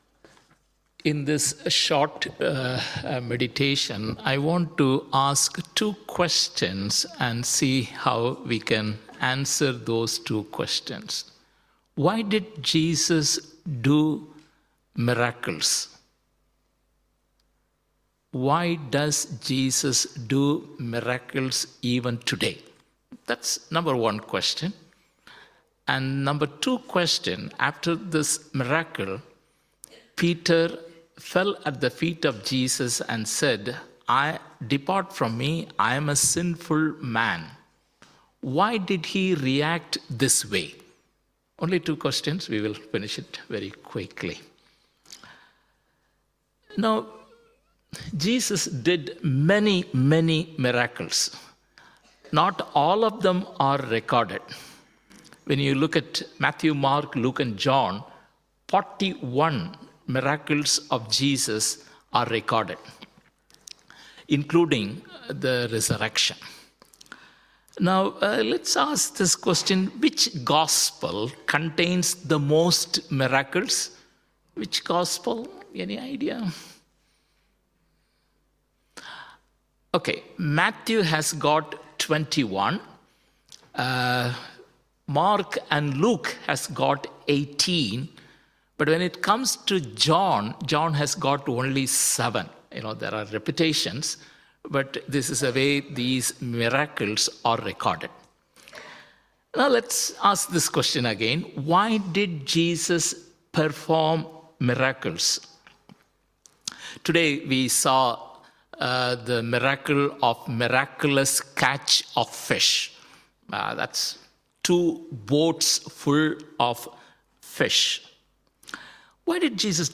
Sermon – February 9 , 2025 9:28